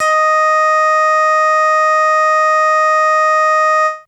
55bc-bse20-d#5.aif